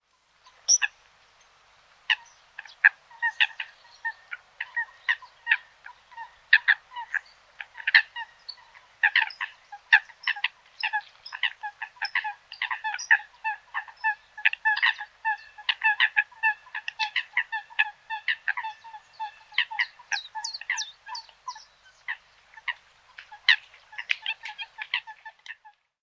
foulque-macroule.mp3